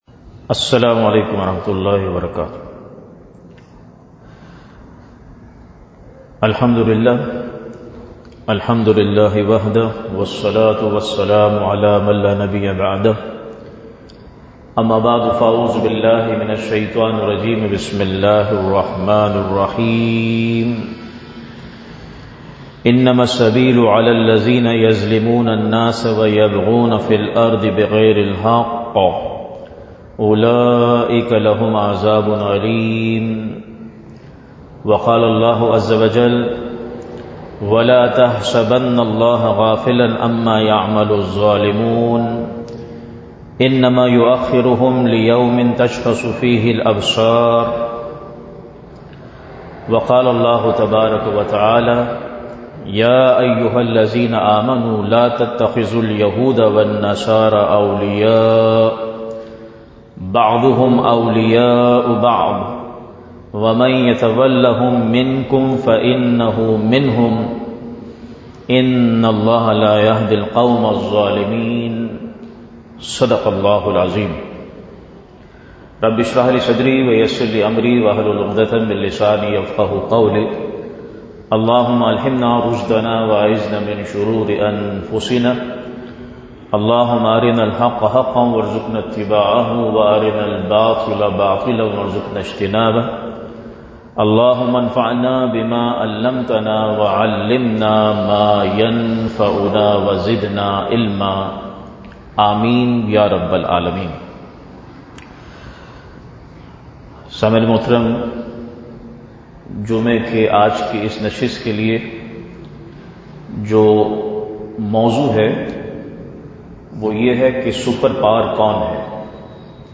Khutbat-e-Jummah (Friday Sermons)
@ Masjid Jame-ul-Quran, Gulshan-e-Maymar Who is the Superpower? | The Abduction of Venezuela’s President – The U.S. President's Open Threat to Muslim Countries.